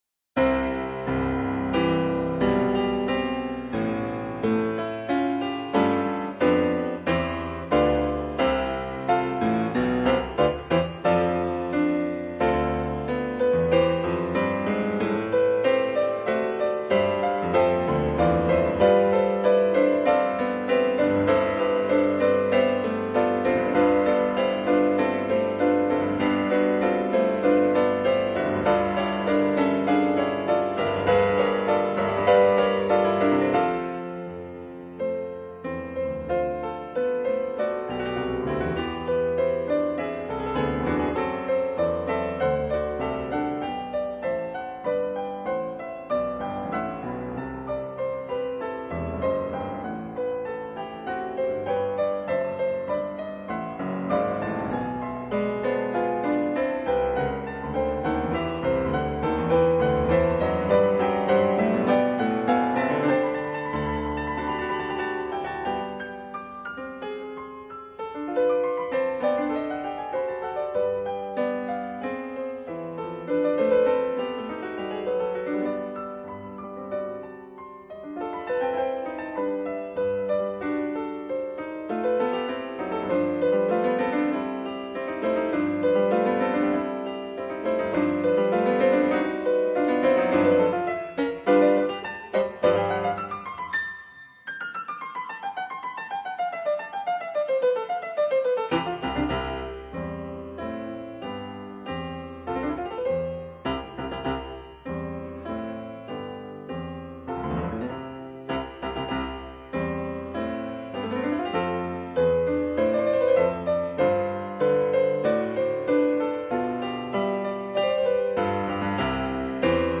Piano Transcription